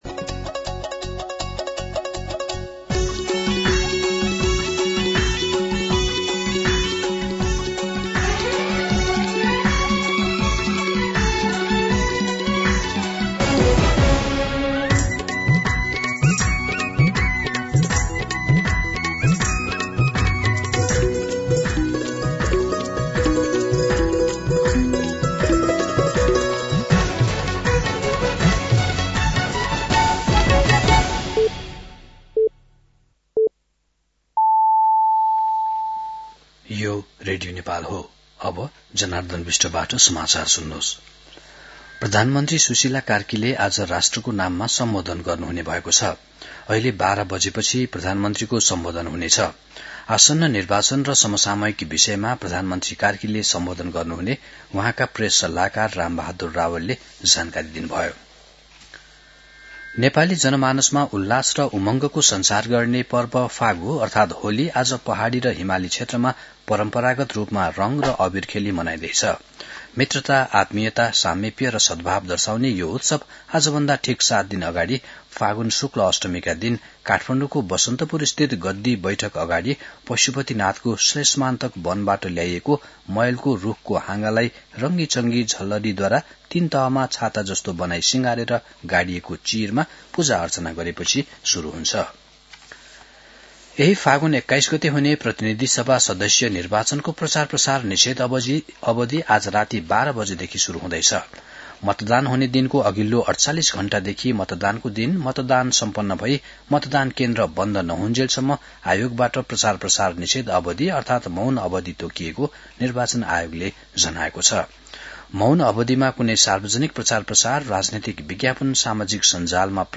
An online outlet of Nepal's national radio broadcaster
मध्यान्ह १२ बजेको नेपाली समाचार : १८ फागुन , २०८२